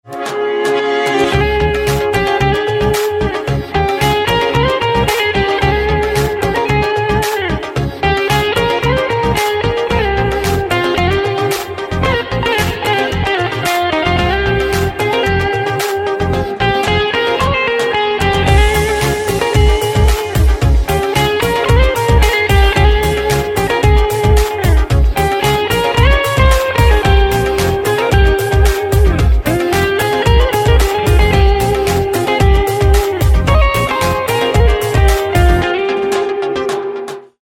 رینگتون پرانرژی و بیکلام